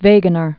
(vāgə-nər), Alfred Lothar 1880-1930.